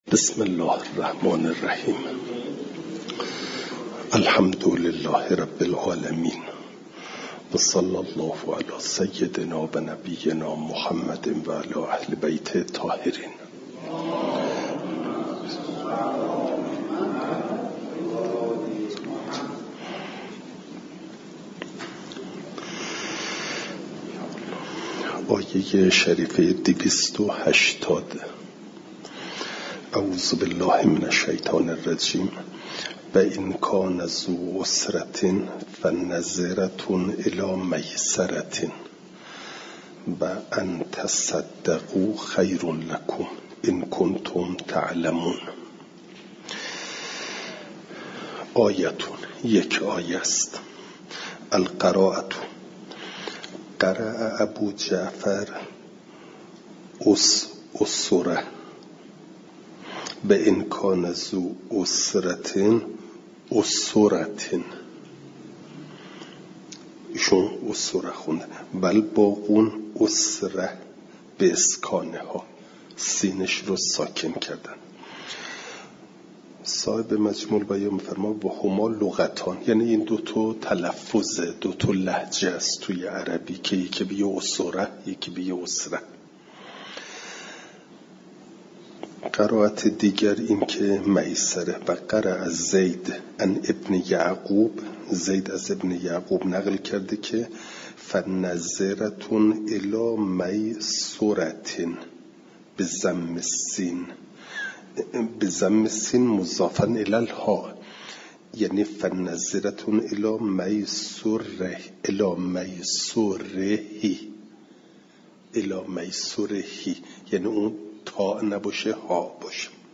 درس تفسیر مجمع البیان